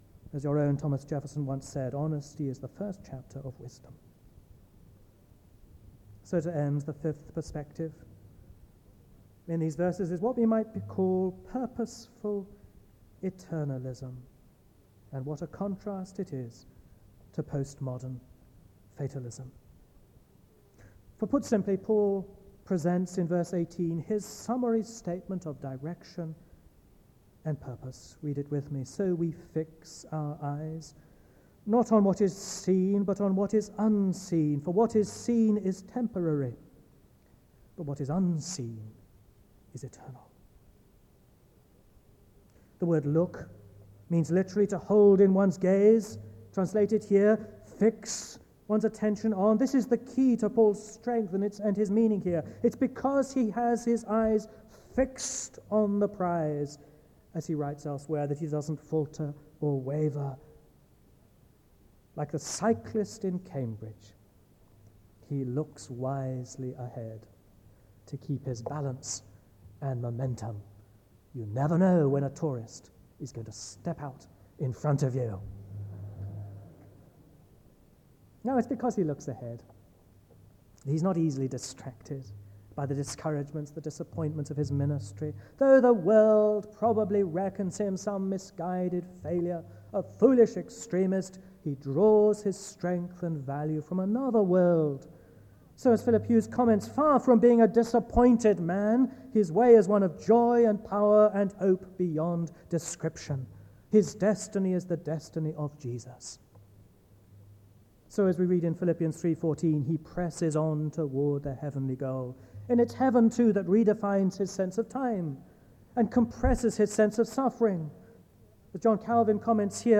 SEBTS Adams Lecture